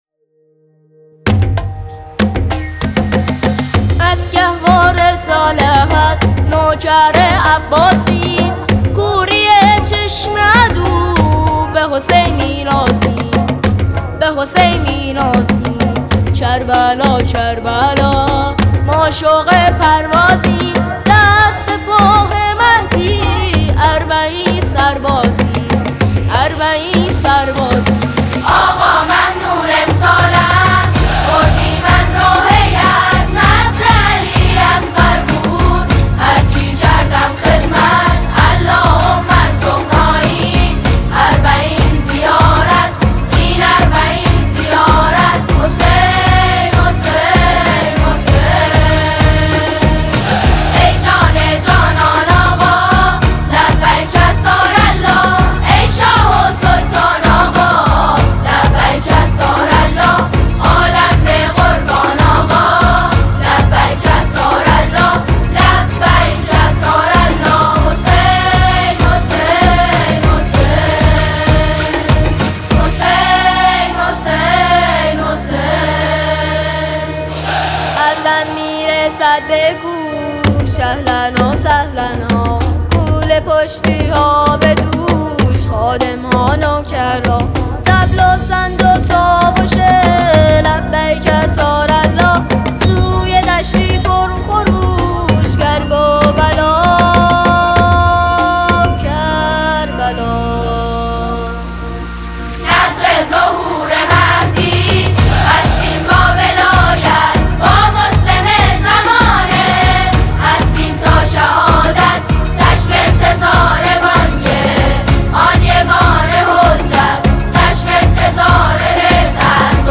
سپاه مهدی (سرود اربعین حسینی)
سپاه مهدی سرود اربعین گروه سرود نورالهدی کانون فرهنگی هنری حضرت جوادالائمه